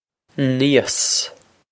níos nee-oss
Pronunciation for how to say
This is an approximate phonetic pronunciation of the phrase.